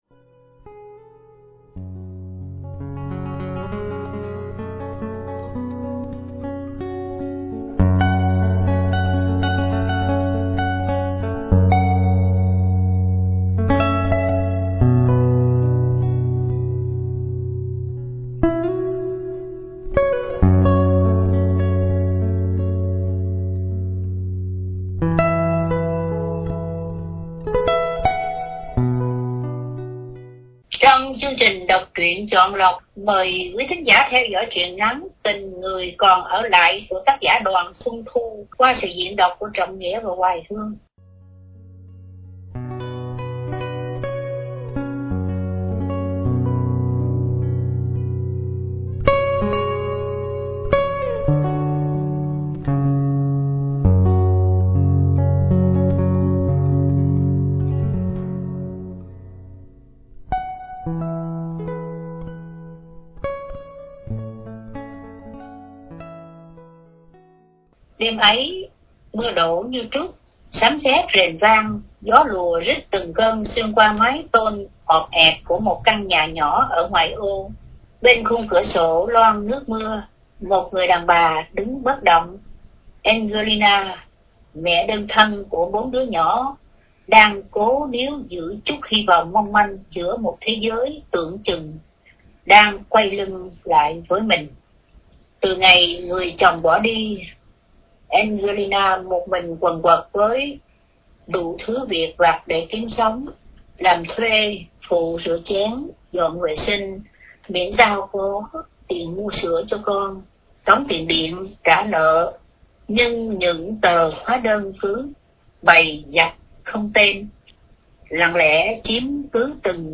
Đọc Truyện Chọn Lọc – Truyện Ngắn ” Tình Người Còn Ở Lại ” – Tác Giả: Đoàn Xuân Thu – Radio Tiếng Nước Tôi San Diego